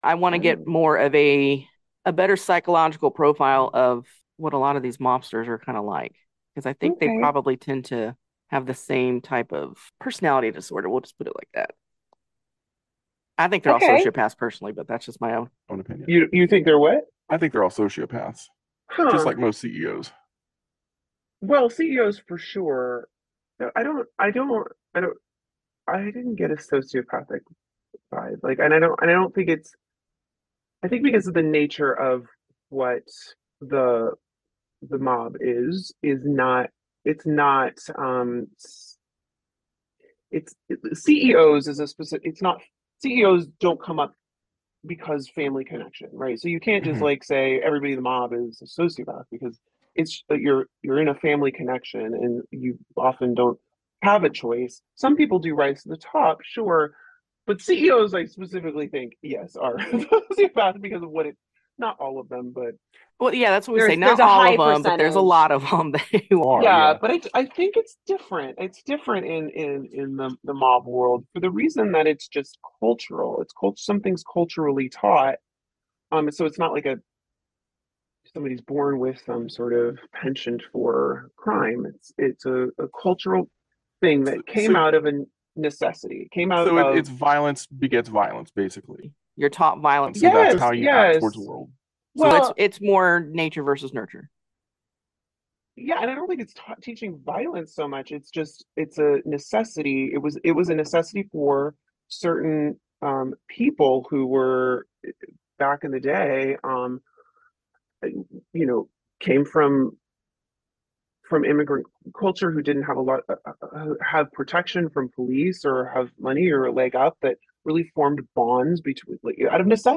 I have changed her voice....and mine also because we talked over each other.